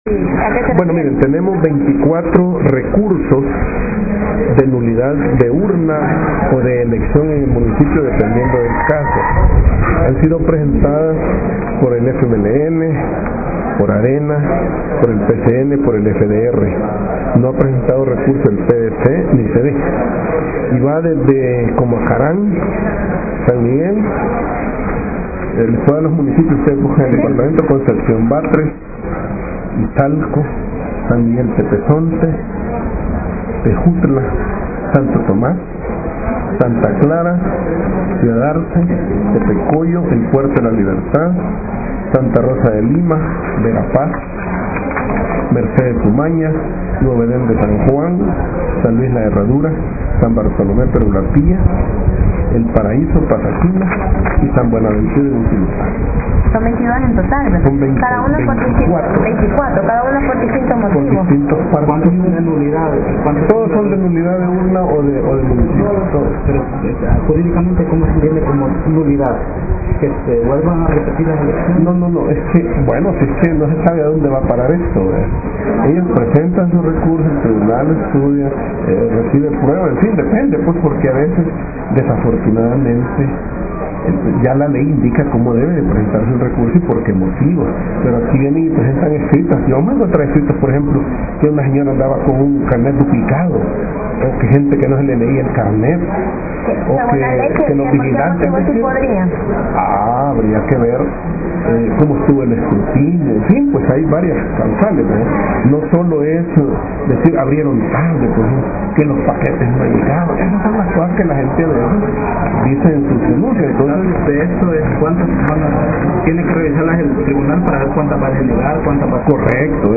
Declaraciones de Eugenio Chicas magistrado del TSE